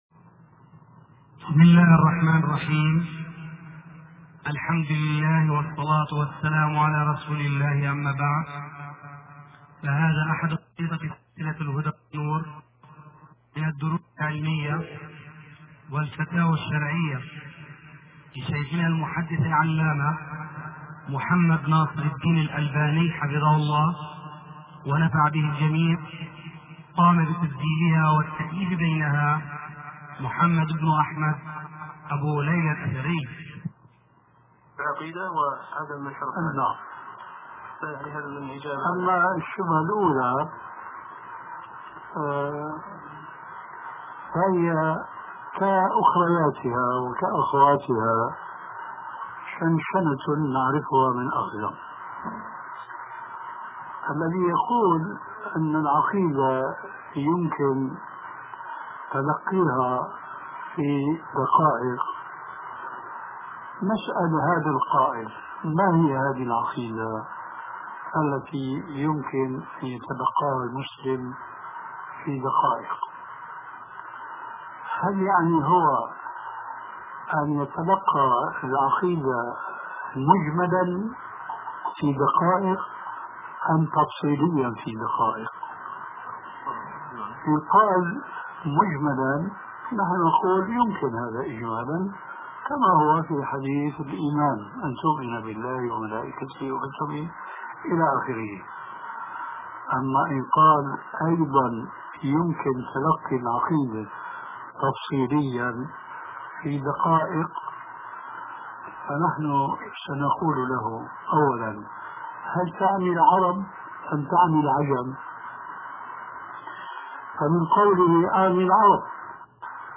شبكة المعرفة الإسلامية | الدروس | كيفية تلقي العقيدة |محمد ناصر الدين الالباني